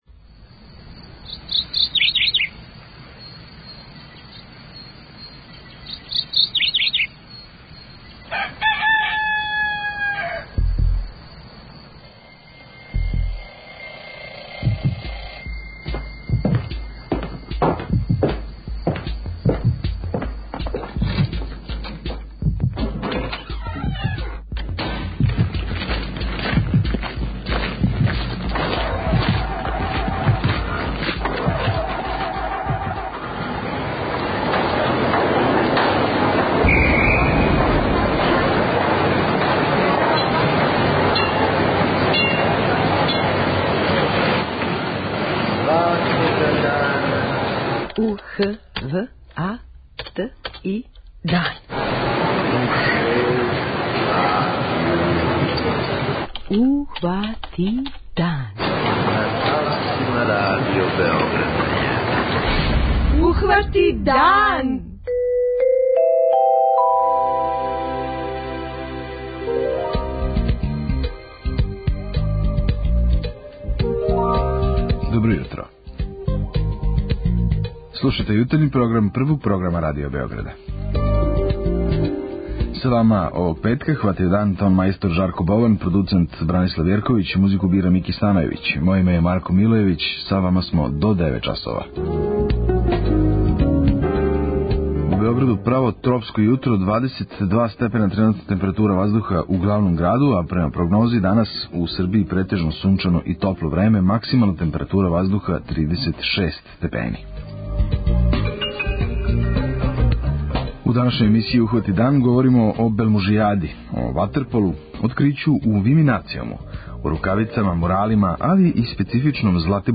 Ухвати дан | Радио Београд 1 | РТС